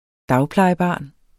Udtale [ ˈdɑwplɑjəˌbɑˀn ]